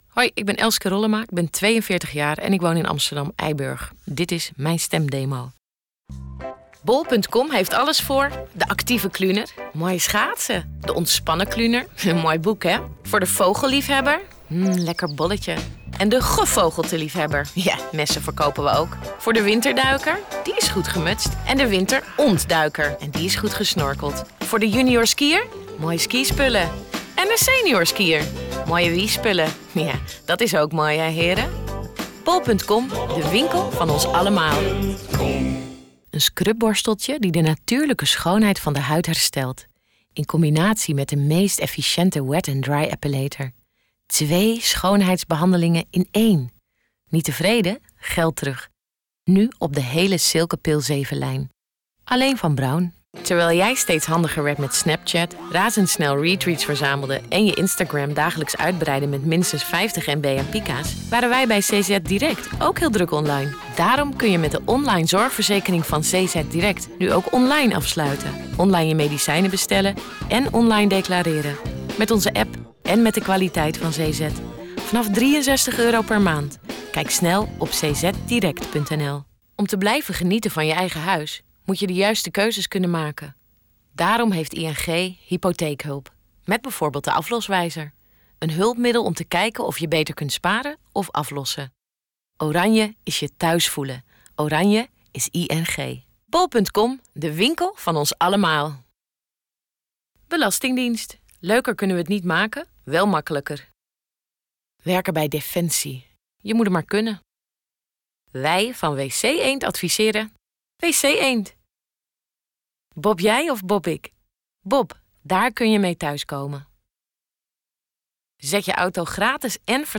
voice demo
Stemacteur, voice - over, zang en